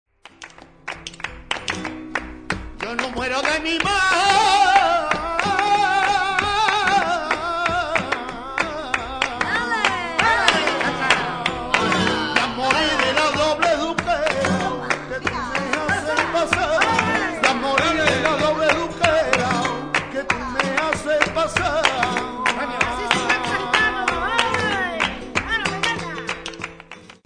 tangos